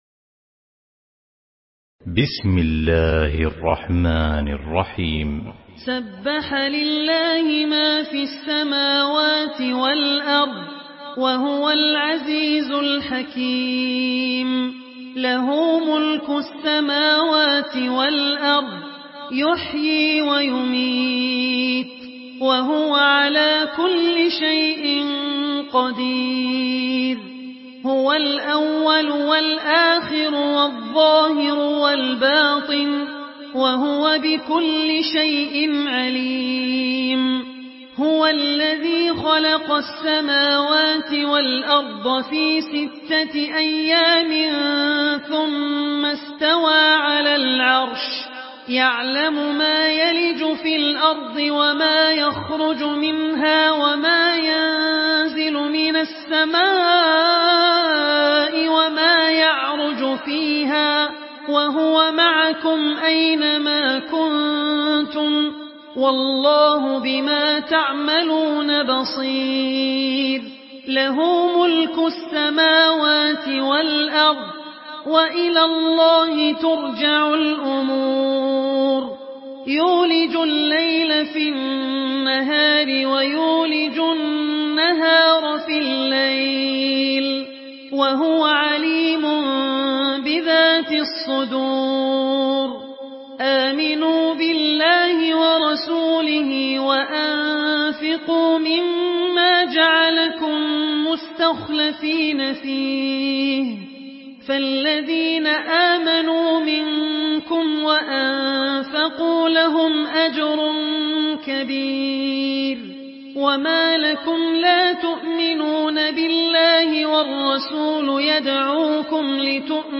Surah আল-হাদীদ MP3 in the Voice of Abdul Rahman Al Ossi in Hafs Narration
Murattal Hafs An Asim